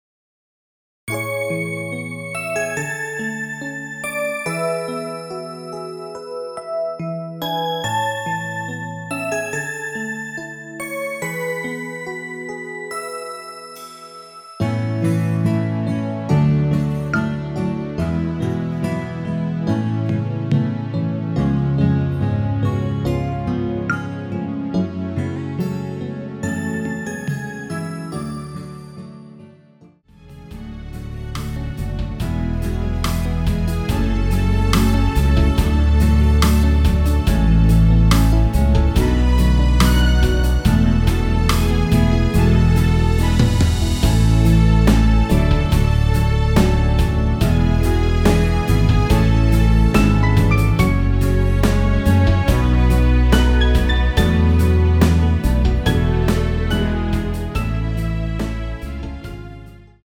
2절과 간주 없이 바로 진행이 됩니다.
◈ 곡명 옆 (-1)은 반음 내림, (+1)은 반음 올림 입니다.
앞부분30초, 뒷부분30초씩 편집해서 올려 드리고 있습니다.
중간에 음이 끈어지고 다시 나오는 이유는